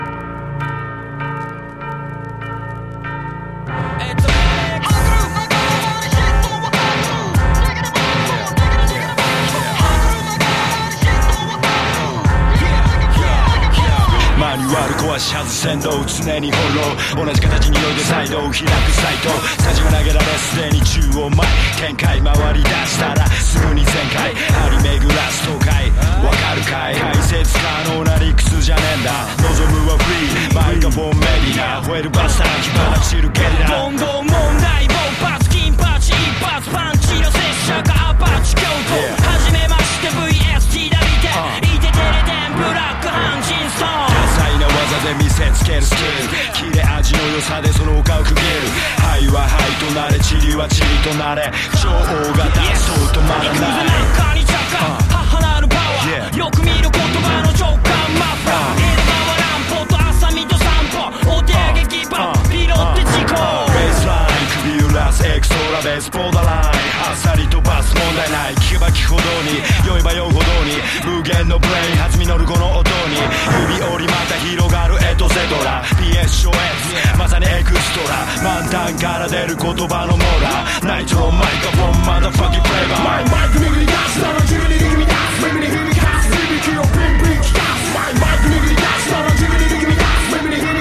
(VOCAL)